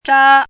chinese-voice - 汉字语音库
zha1.mp3